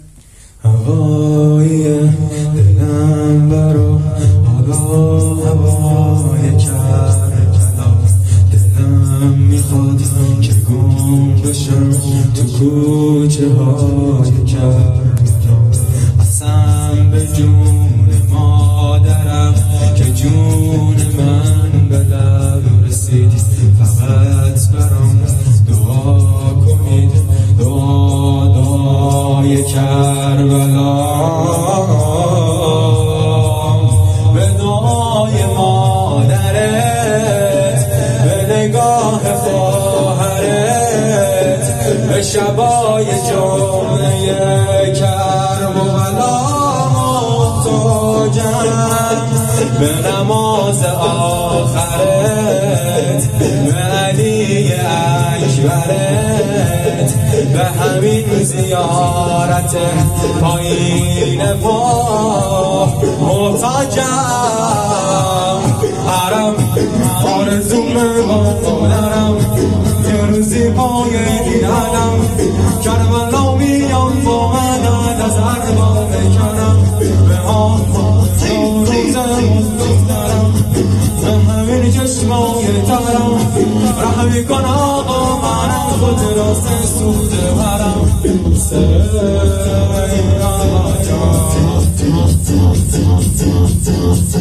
مداحی شور شور محرم